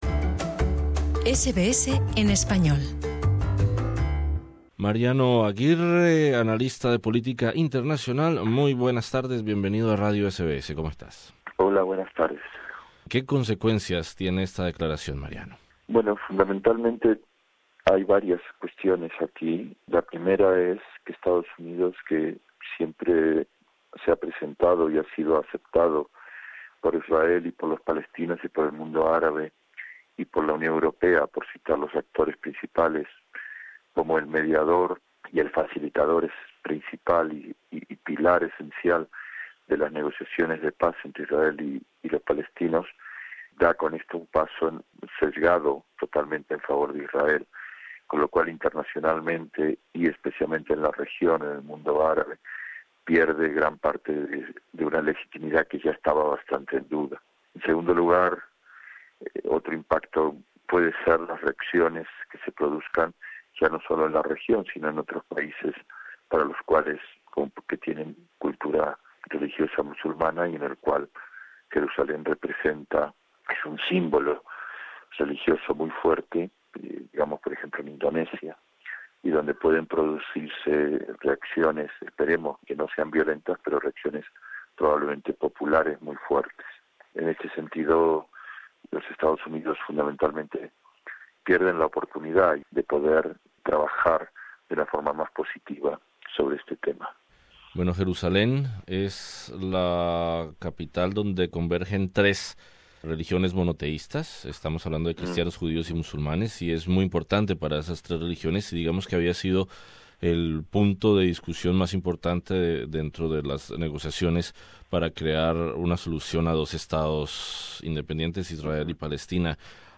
entrevistamos al experto en conflictos y política internacional